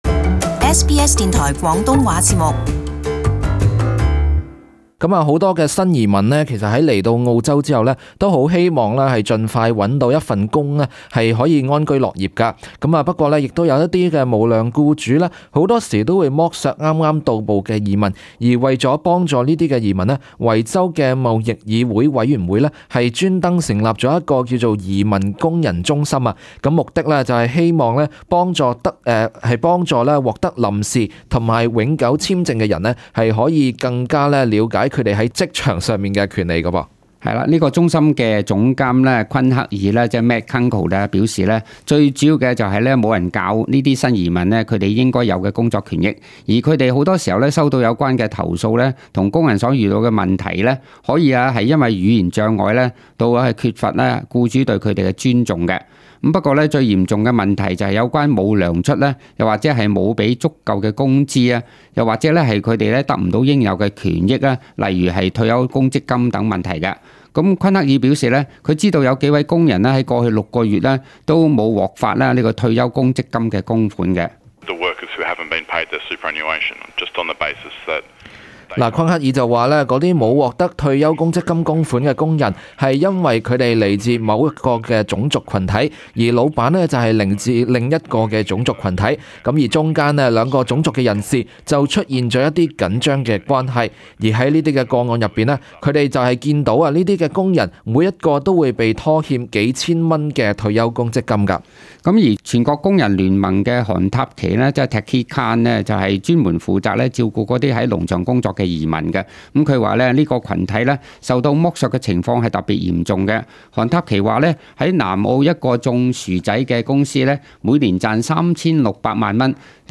【時事報導】維州成立移民工人中心